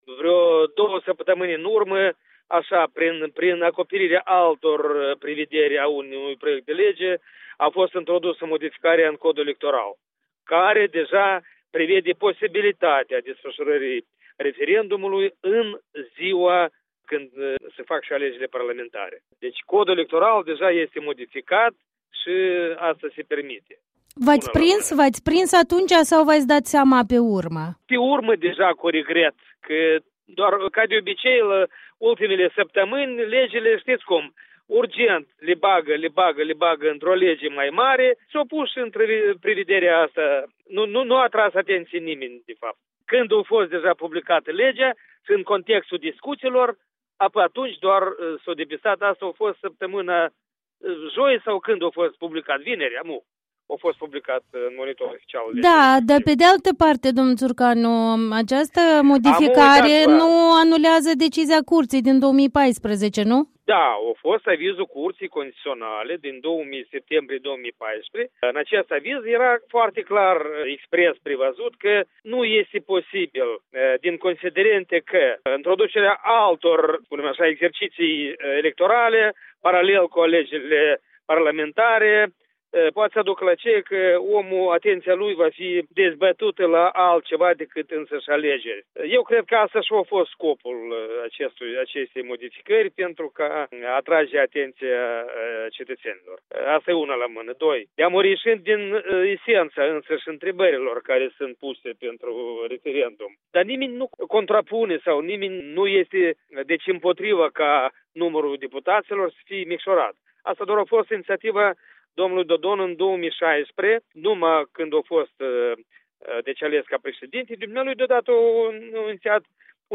Un interviu cu deputatul socialist despre referendumul consultativ ce urmează să fie organizat concomitent cu alegerile parlamentare din februarie 2019.